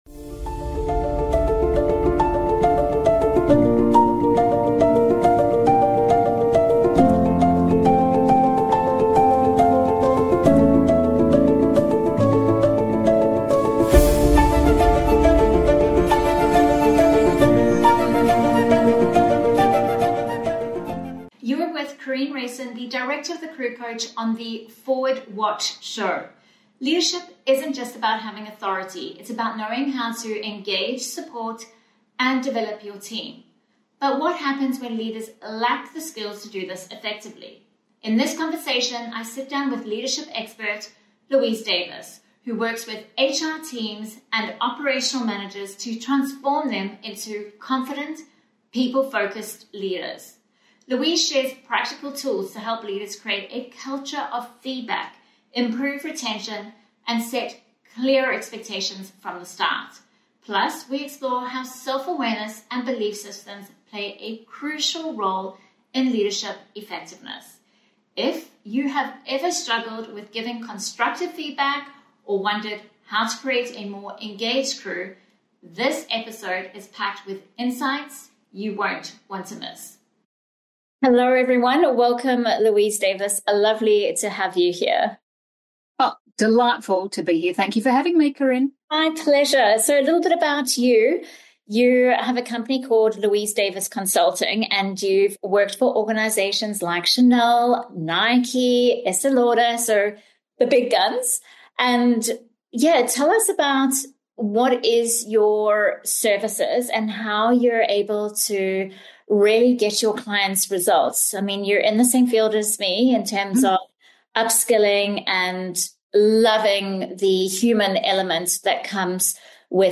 Key Discussion Points